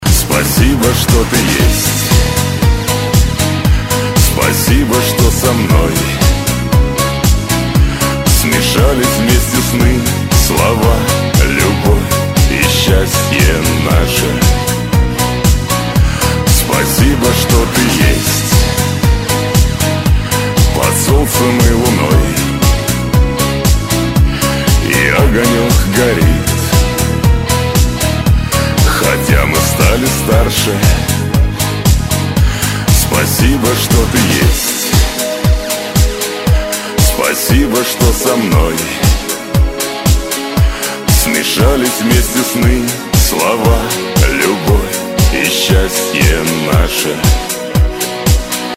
Шансон рингтоны